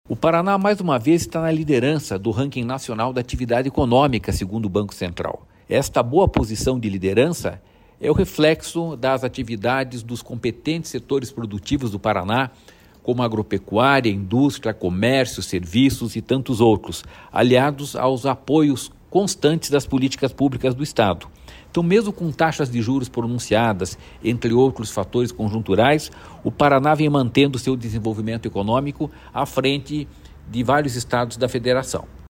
Sonora do diretor-presidente do Ipardes, Jorge Callado, sobre o Paraná ter o maior crescimento econômico do Brasil nos primeiros cinco meses de 2025